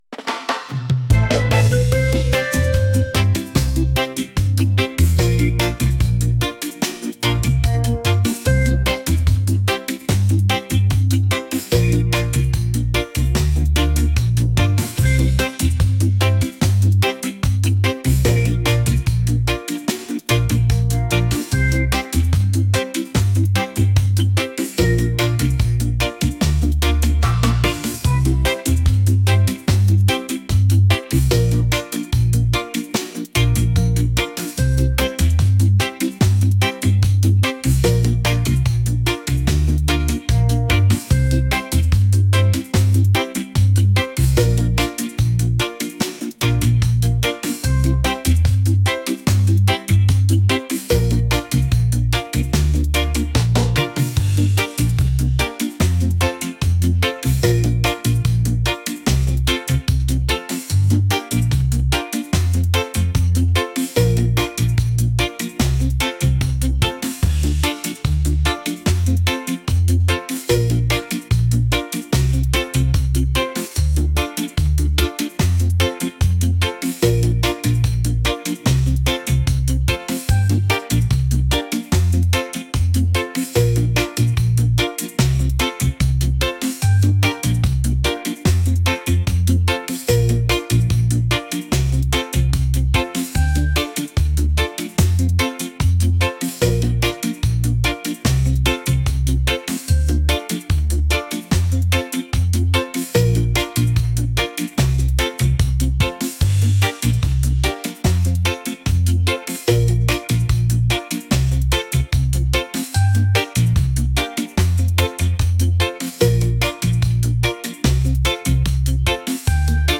rhythmic | reggae